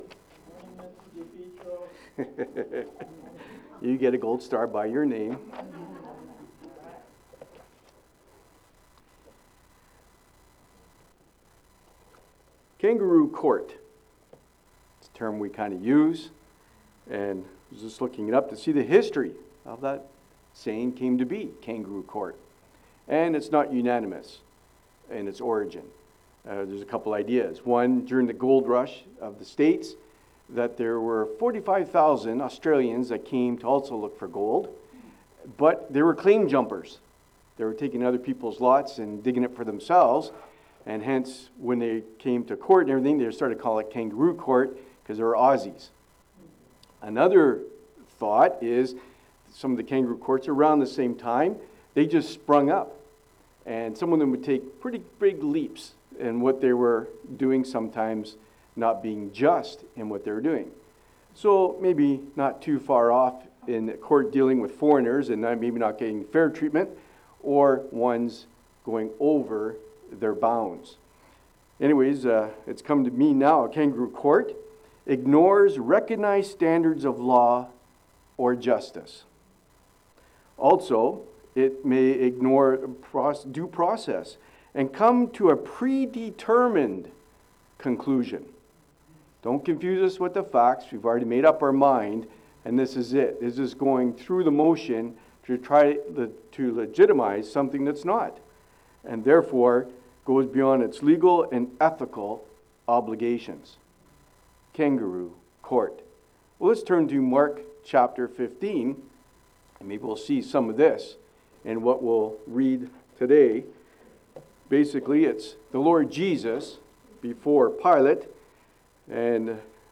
Mark 15:1-15 Service Type: Sunday Morning « Mark 14:66-72 Peter’s Denial 2023 Father’s Day